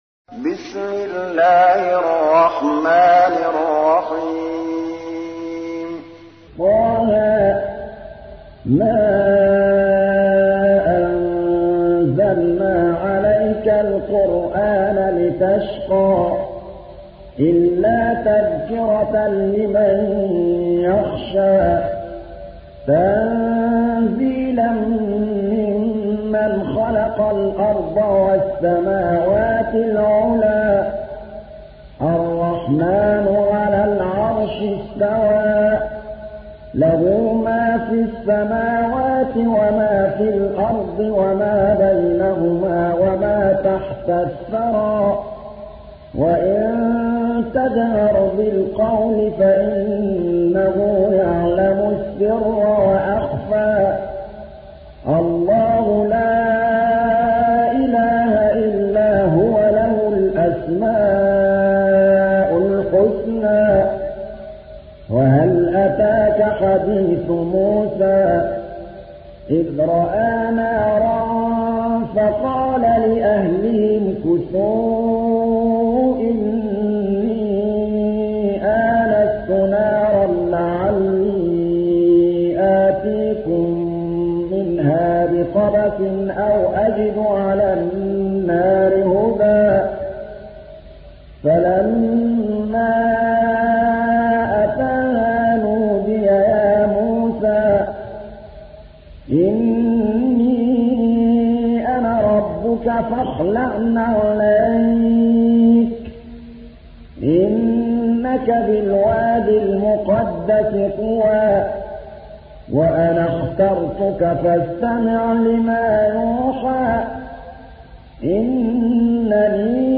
تحميل : 20. سورة طه / القارئ محمود الطبلاوي / القرآن الكريم / موقع يا حسين